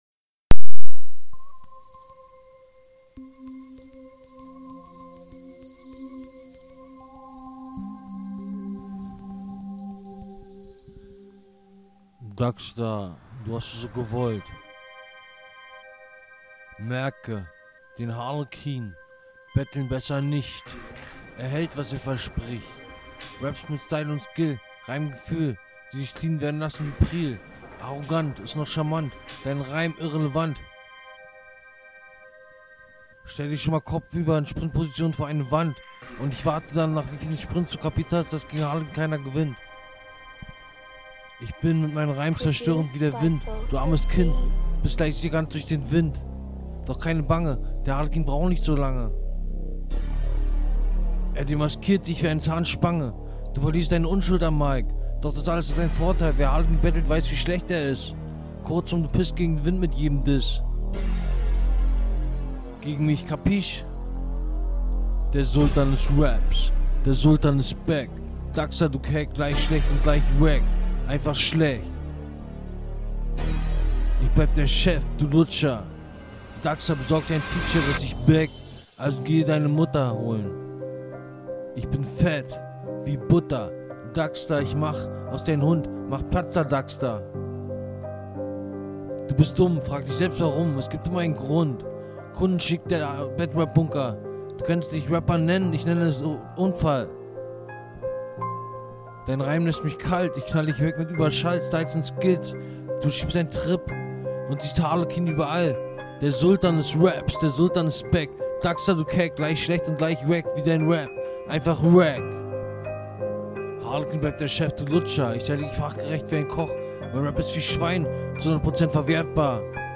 Soundbild: Leg dir nen popschutz zu und du nuschelst extrem ich verstehe die hälfte nicht …